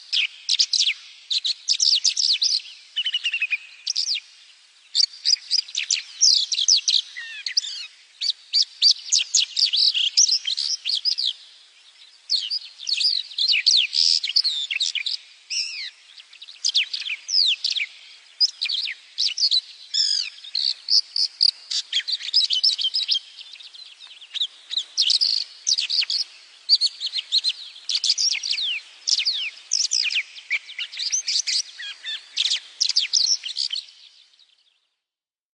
黄胸织布鸟叫声